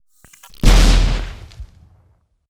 Grenade5.wav